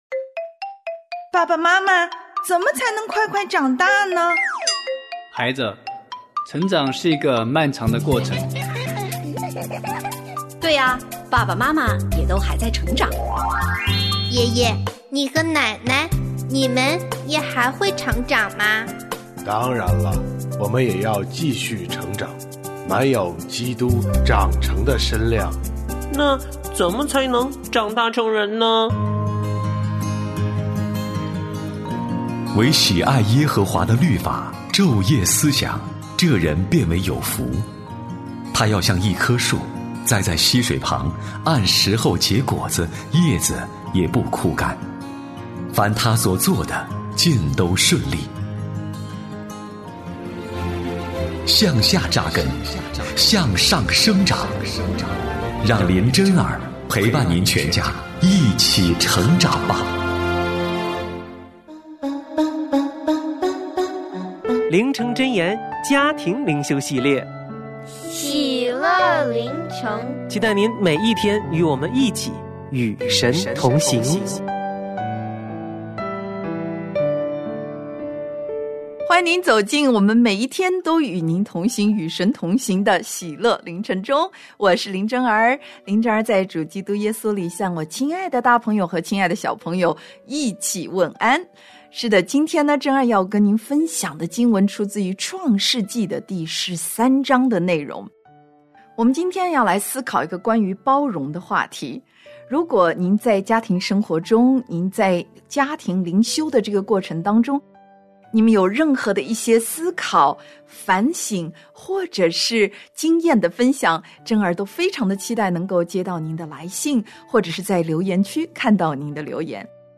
我家剧场：圣经广播剧（137）以利亚责备亚哈；南北两国联合攻打拉末